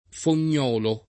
fognolo [ fon’n’ 0 lo ] s. m.